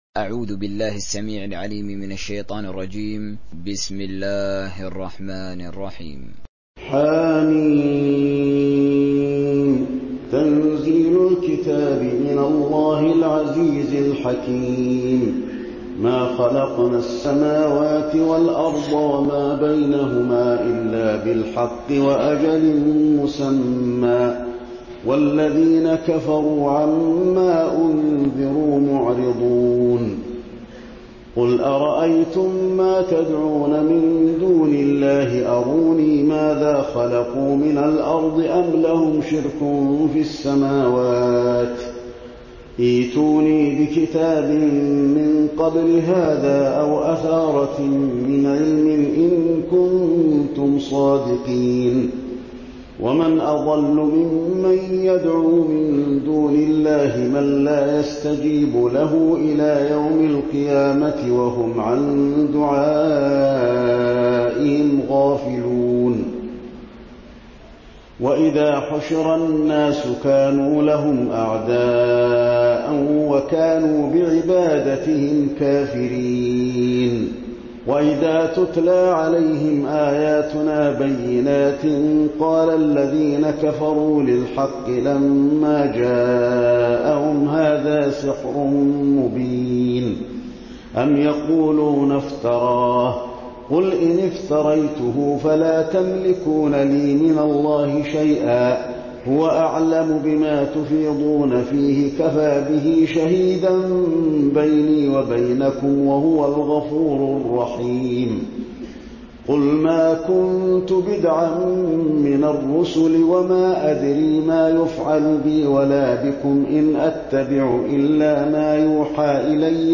تحميل سورة الأحقاف mp3 حسين آل الشيخ تراويح (رواية حفص)
تحميل سورة الأحقاف حسين آل الشيخ تراويح